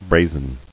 [bra·zen]